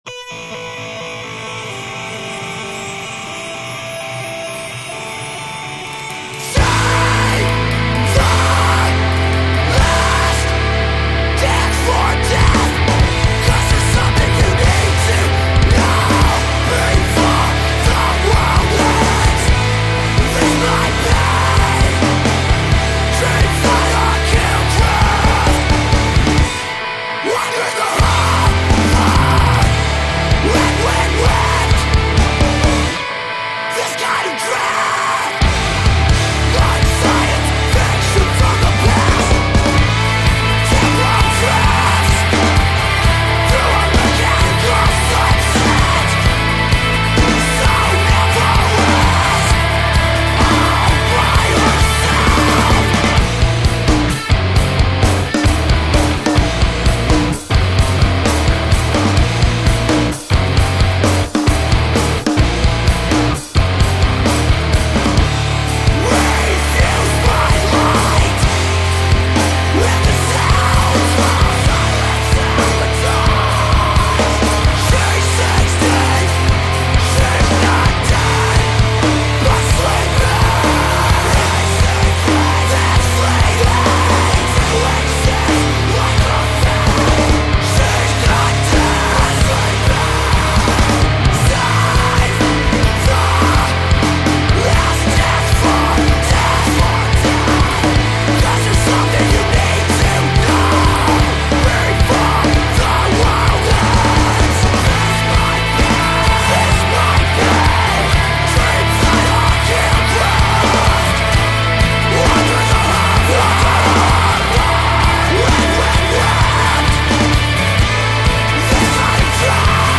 vocals are typical metalcore